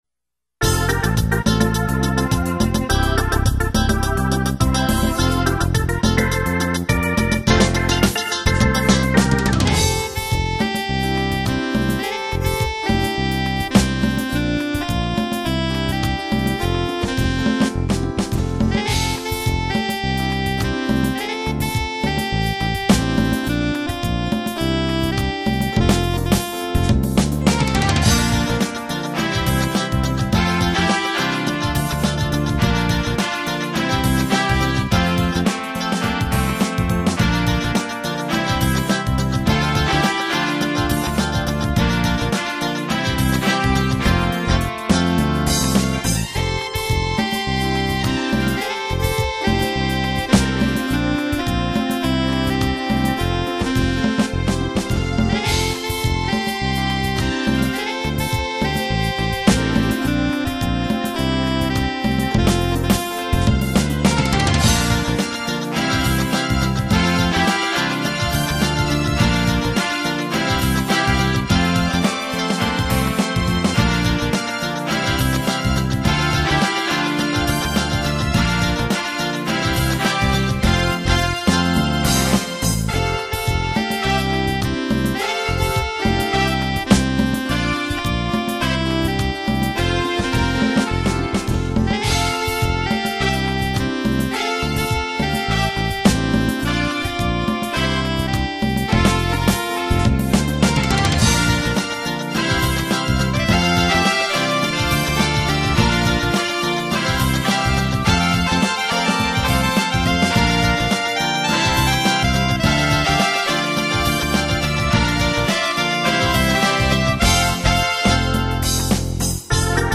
Podkład muzyczny tytuł